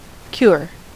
Ääntäminen
IPA : /kjʊɹ/